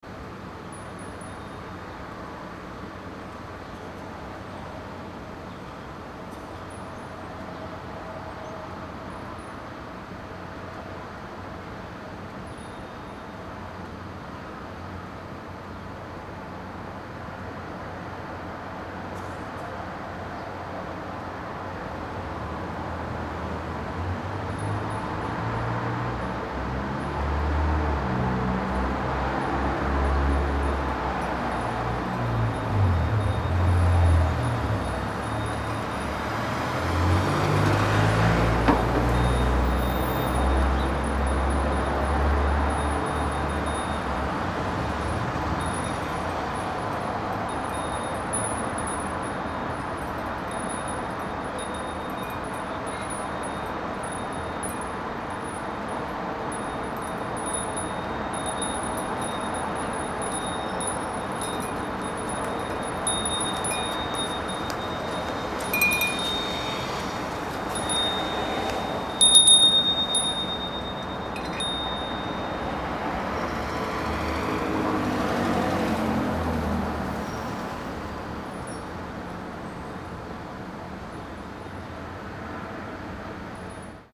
Just as I was finishing, a man walking by the front door stopped and studied my microphone and recorder setup on the stoop.
However, the driver shut off the engine, dropped the seat back and settled in for a little nap.